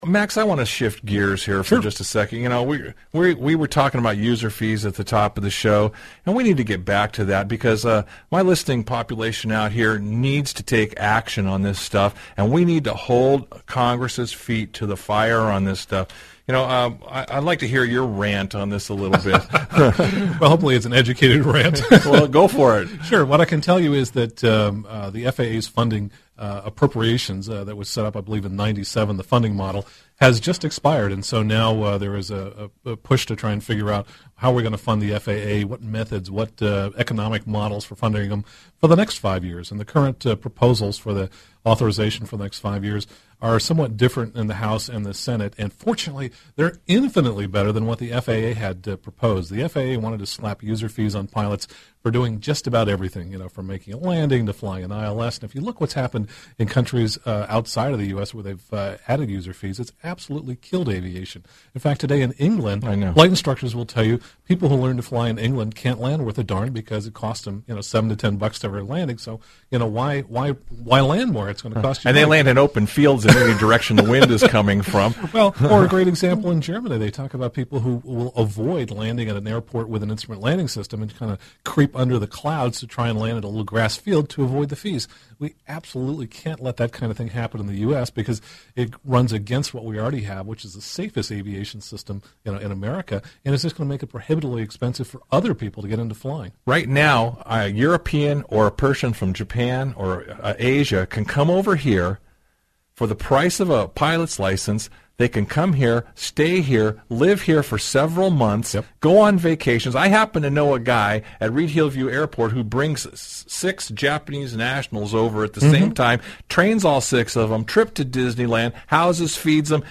Radio Interview